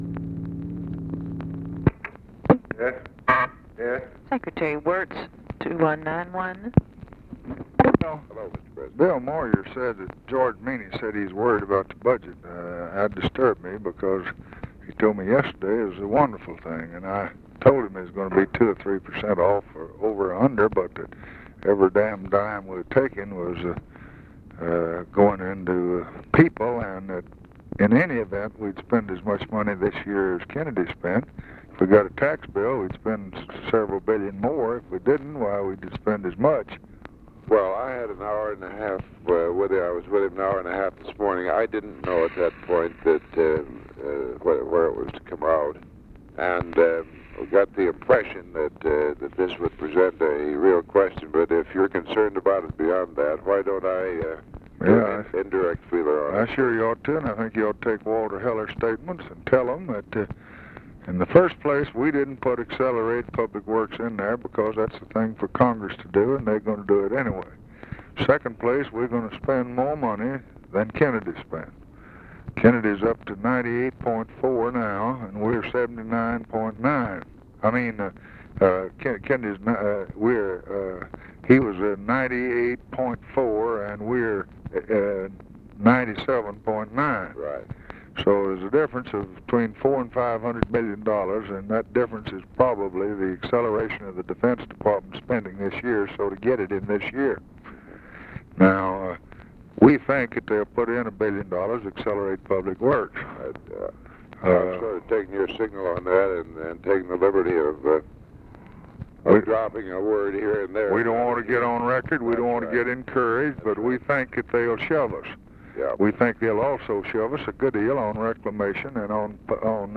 Conversation with WILLARD WIRTZ, January 8, 1964
Secret White House Tapes